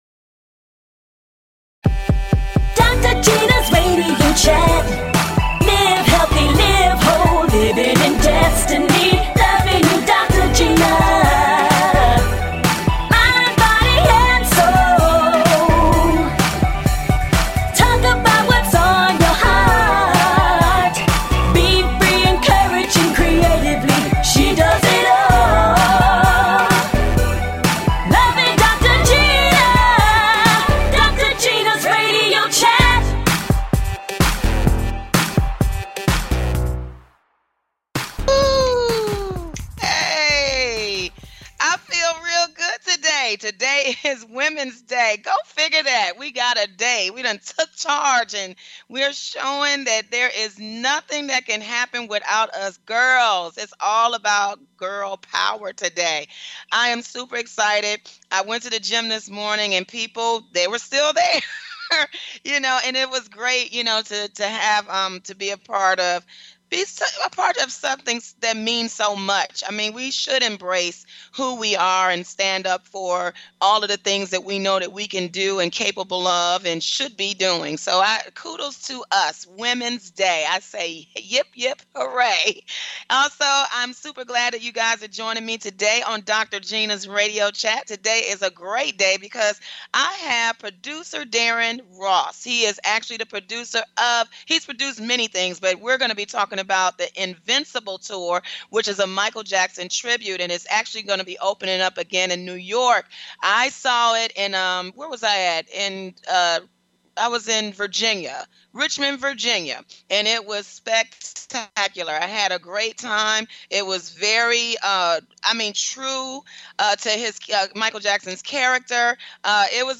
A talk show of encouragement.